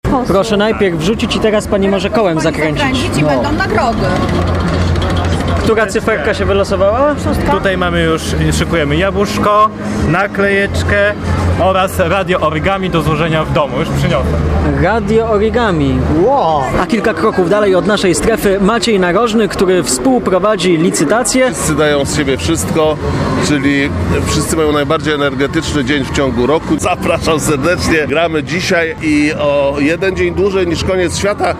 Na parterze pawilonu Congress Center na Międzynarodowych Targach Poznańskich stanęło nasze plenerowe studio, skąd nadawaliśmy nasz program.